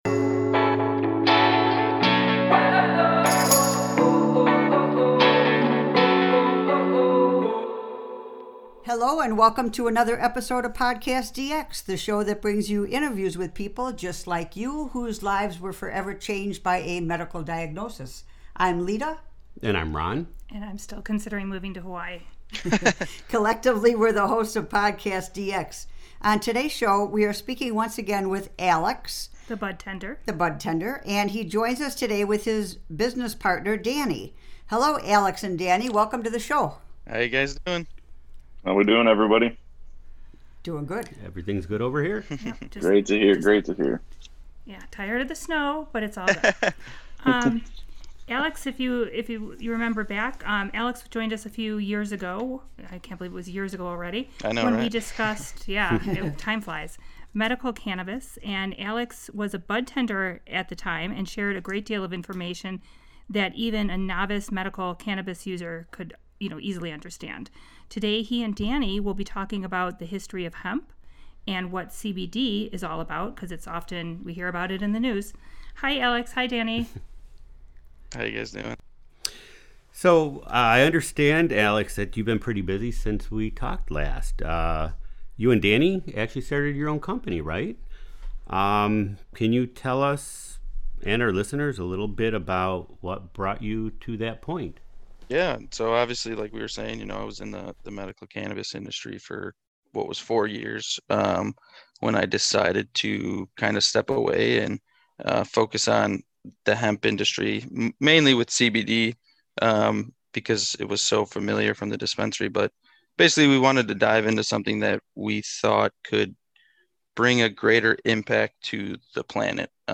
We are super excited to be posting our first video interview with this episode as well!!